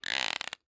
assets / minecraft / sounds / mob / dolphin / idle3.ogg